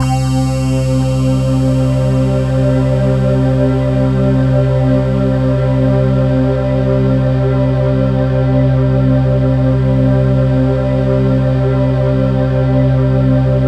Index of /90_sSampleCDs/Infinite Sound - Ambient Atmospheres/Partition C/03-CHIME PAD
CHIMEPADC3-R.wav